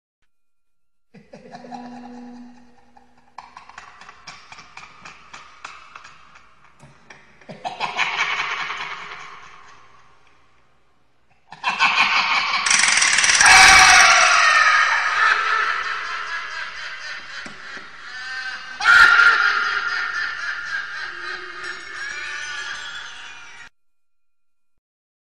Evil Laugh Sound Effect
creepy emotion laugh sound effect free sound royalty free Funny